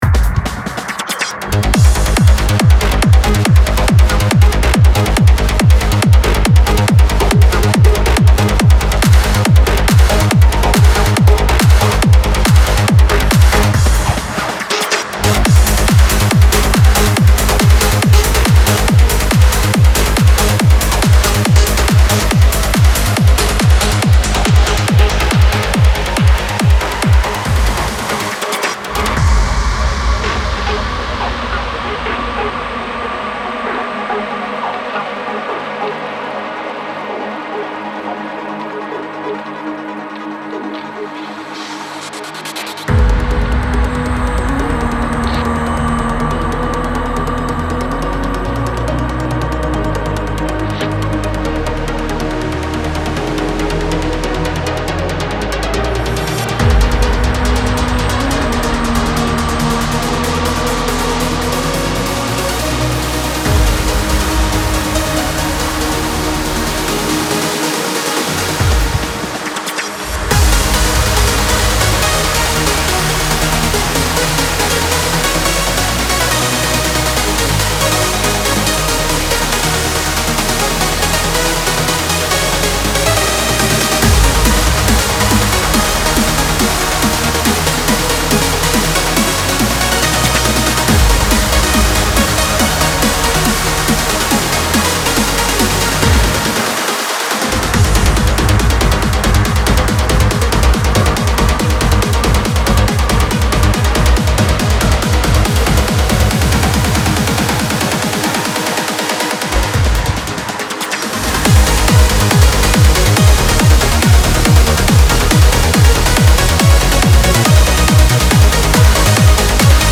• Жанр: Trance